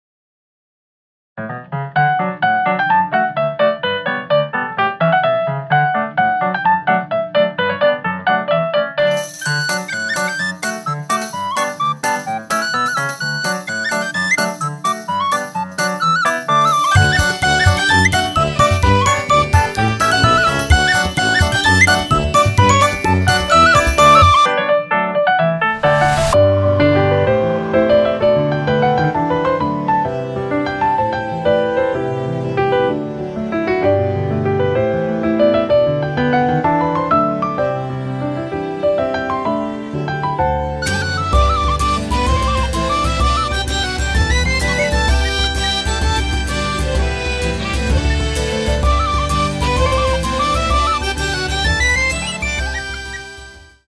飼い猫ちゃんをイメージしたアイリッシュというリクエストでした！